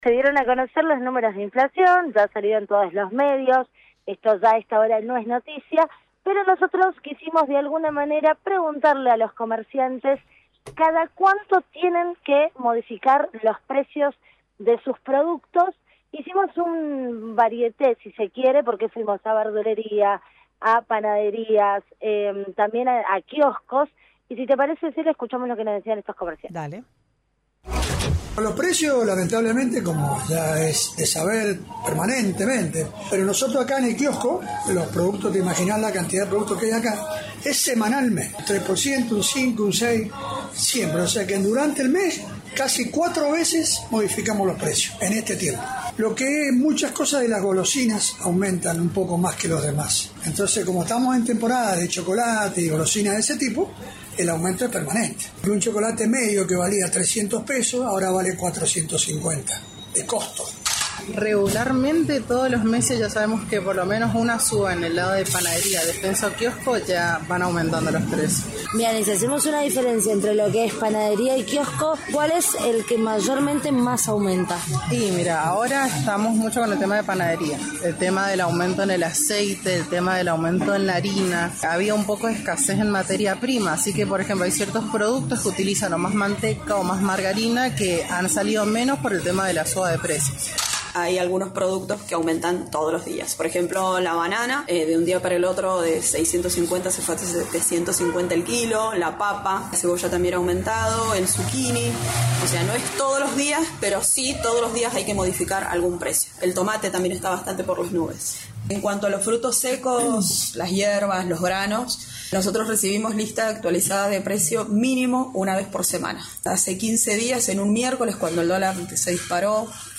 LVDiez - Radio de Cuyo - Móvil de LVDiez- recorrida por comercios tras el 6% de inflación a nivel nacional